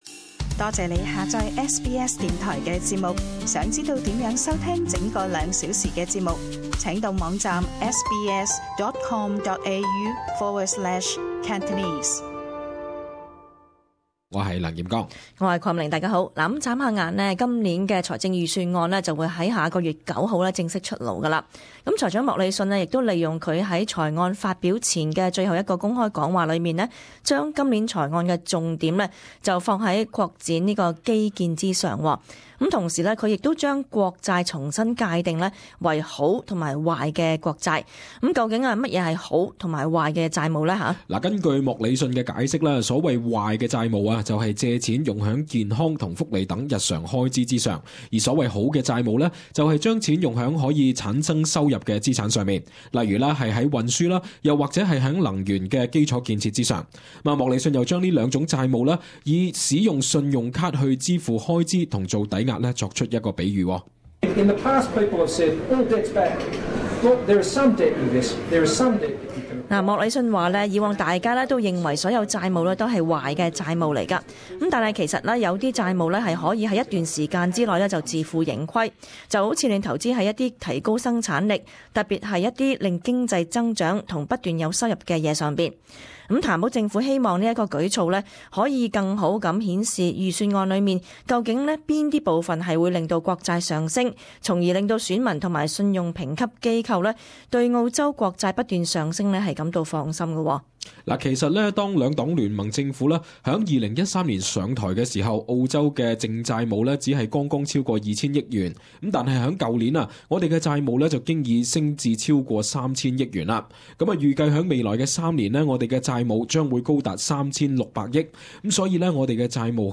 【時事報導】甚麼是「好」債？ 甚麼是「壞」債？